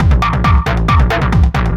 DS 136-BPM A5.wav